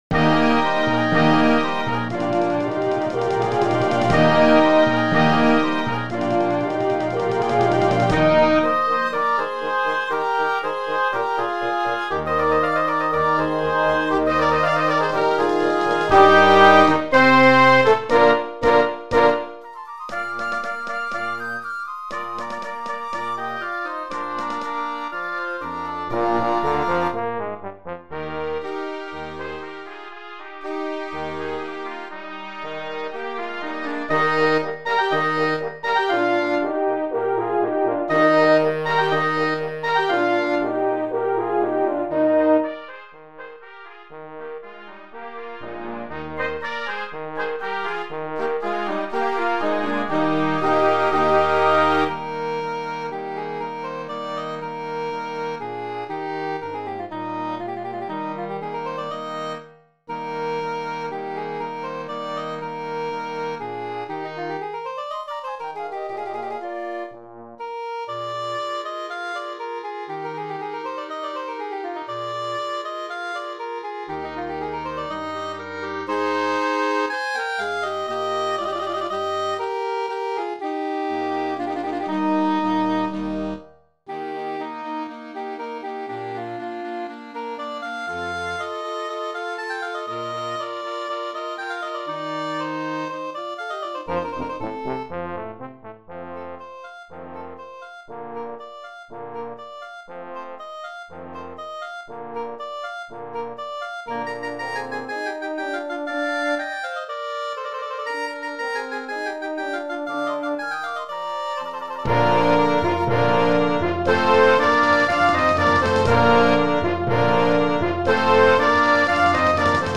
Voicing: English Horn and Band